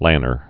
(lănər)